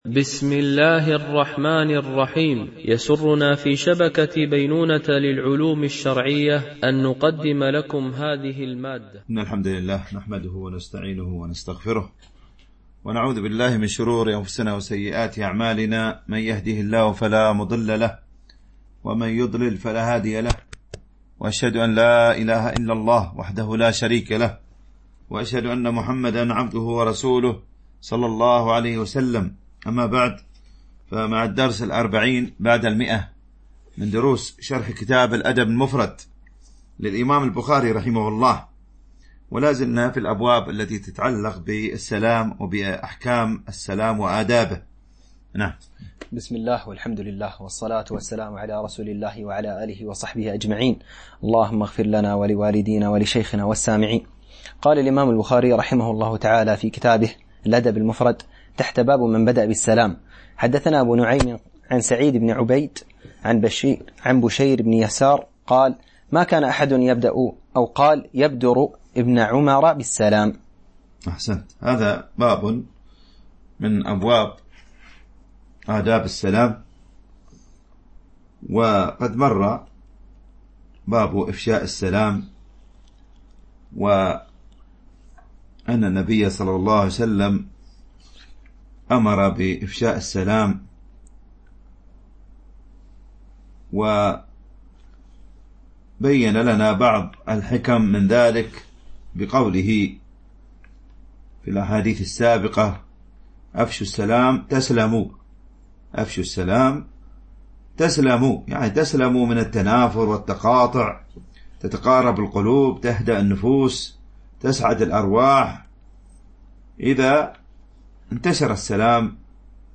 شرح الأدب المفرد للبخاري ـ الدرس 140 ( الحديث 982 - 988 )